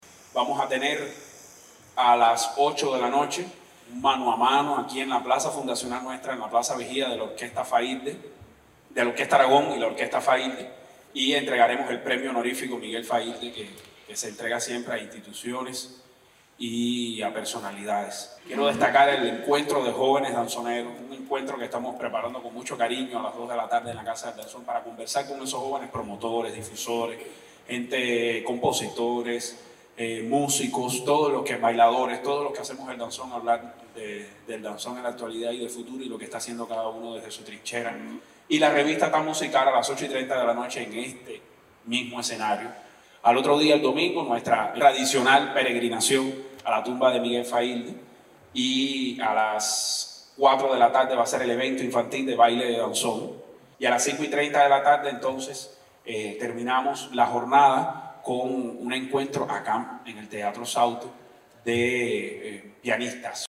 Durante la conferencia de prensa previa al evento, realizada en el Teatro Sauto, de la ciudad de Matanzas